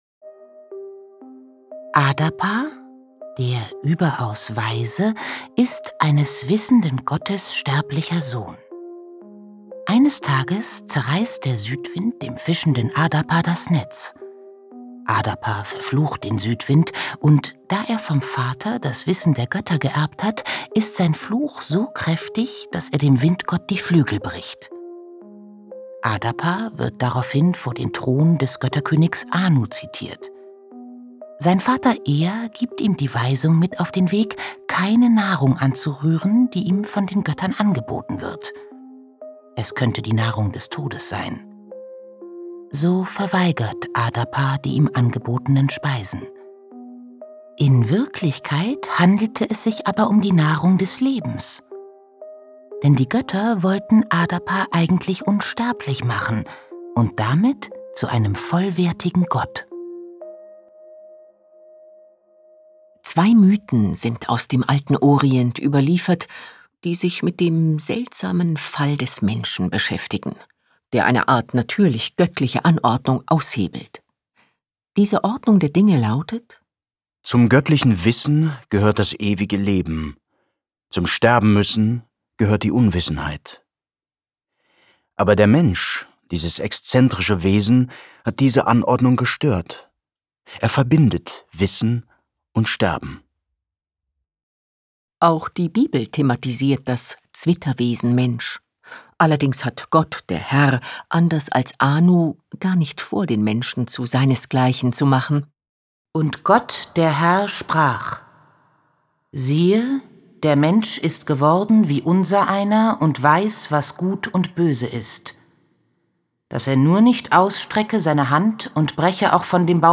Ein Hörspaziergang
Begehbarer Hörspiel-Parcour